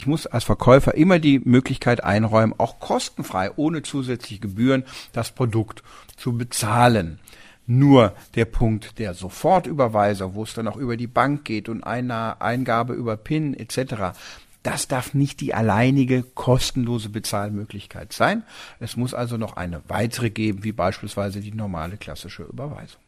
O-Ton: Sofortüberweisung darf nicht einzige kostenlose Zahlungsmöglichkeit sein
DAV, O-Töne / Radiobeiträge, Ratgeber, Recht, , , , , ,